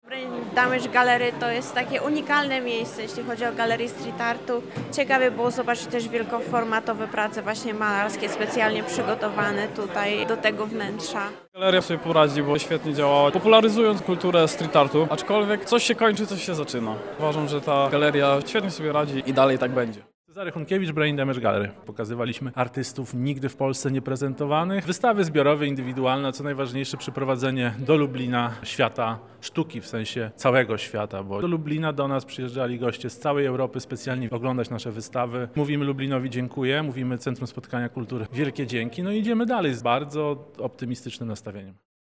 Na miejscu był nasz reporter: